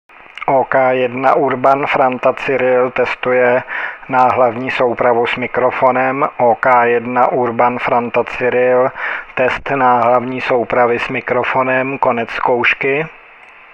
Koupil jsem k plné spokojenosti náhlavní soupravu (sluchátka a mikrofon) Sennheiser, typ PC8:
Hluk pozadí je účinně potlačován, takže nejen, že není slyšet funící ventilátor notebooku, ale dokonce nebyl slyšet ani hlasitý telefonní hovor vedený asi 2 metry před mikrofonem, nebylo slyšet ani klikání myši a ani poklepávání do pouzdra notebooku.
NF úroveň je dostatečná, modulace je slušná,
test_mikrofonu.wma